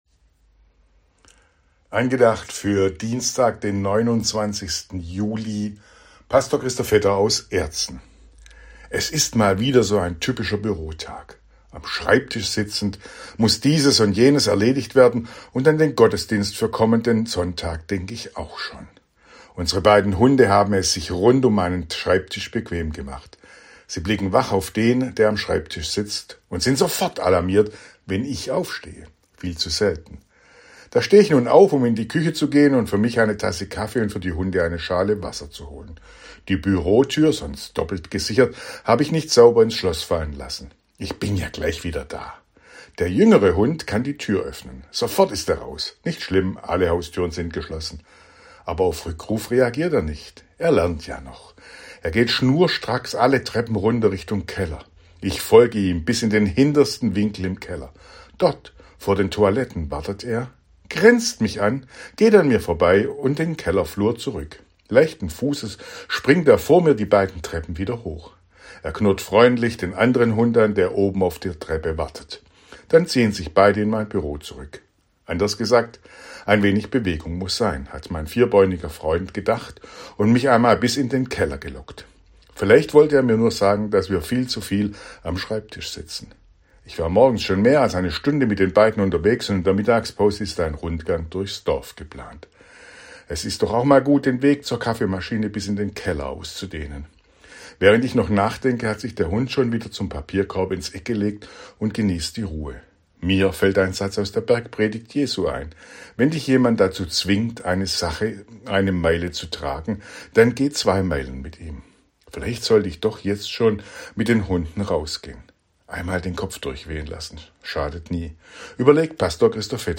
Radioandacht vom 29. Juli